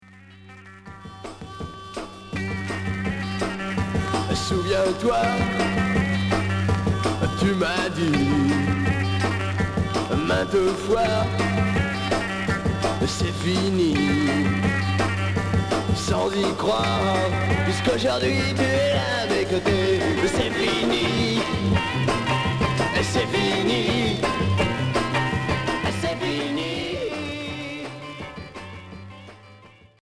Chanson rock et garage